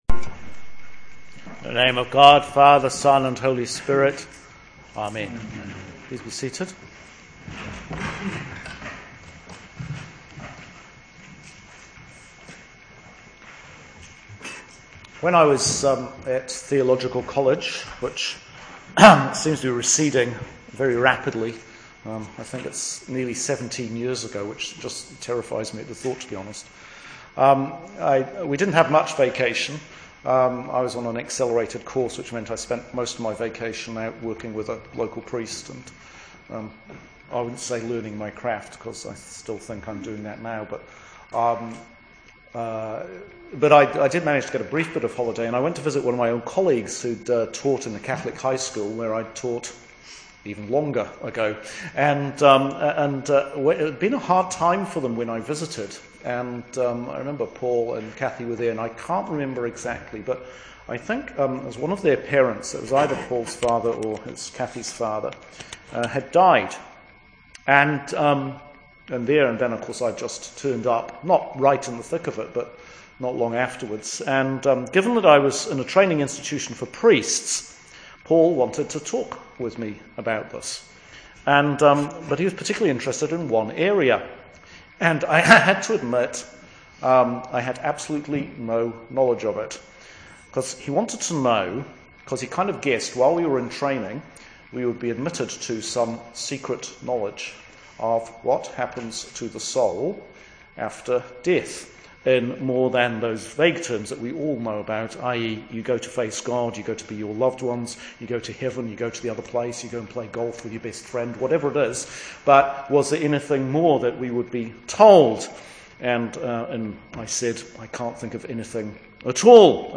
Sermon for Evensong Sunday next before Lent – Year B